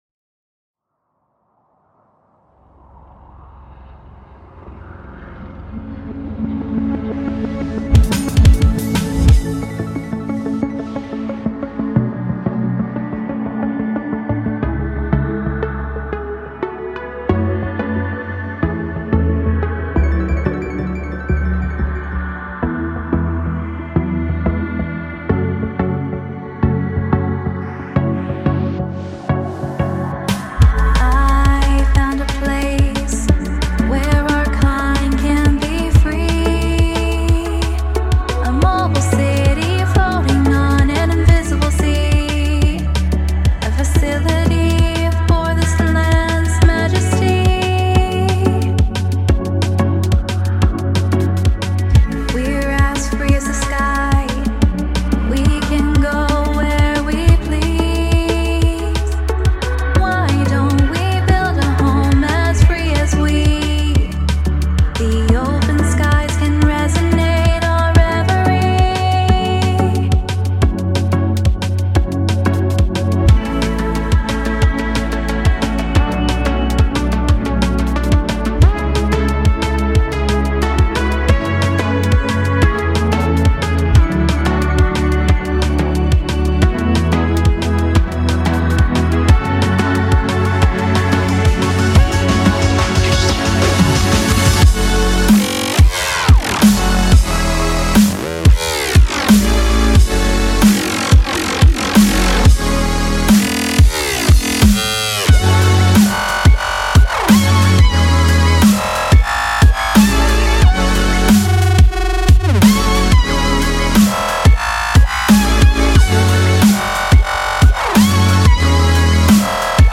Her voice is incredible.
BPM: 180 Key: Amajor/F#minor